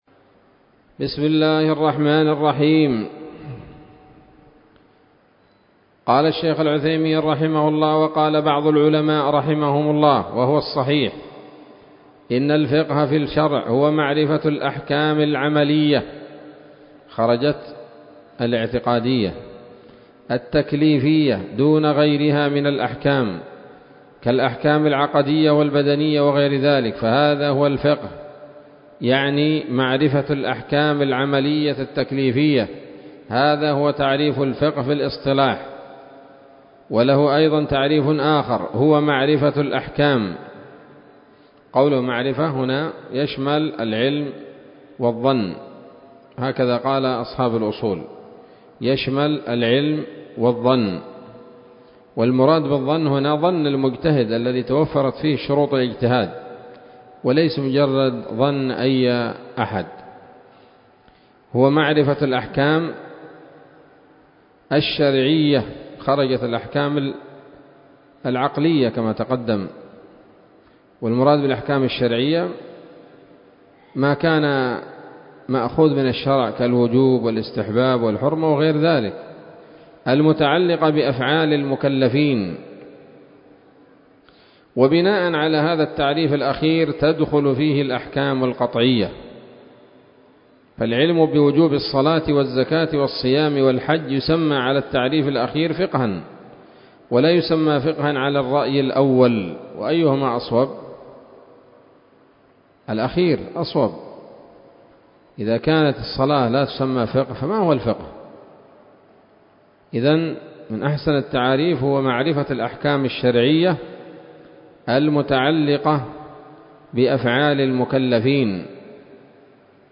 الدرس السابع من شرح نظم الورقات للعلامة العثيمين رحمه الله تعالى